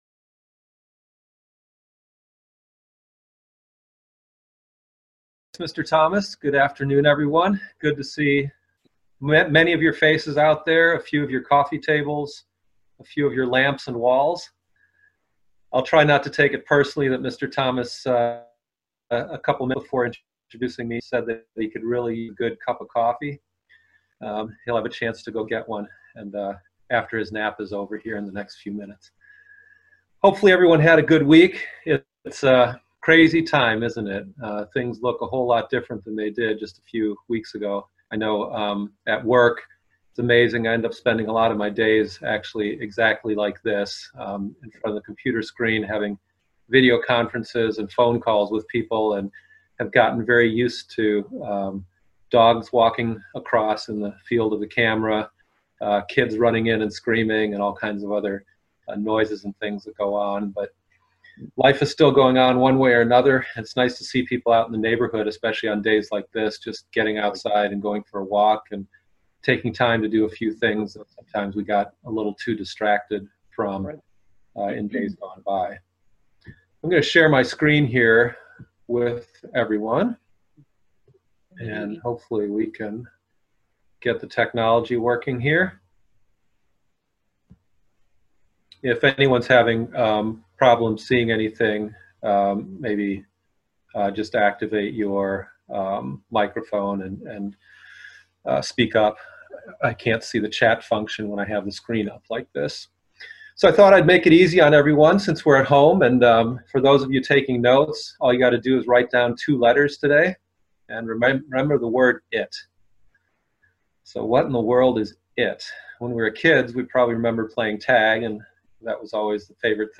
Local Webcast - As we think about the spring Holy Days let's think about the "It" factor and see what we can discover. "It" is God's victory... dominant, personal, complete and lasting.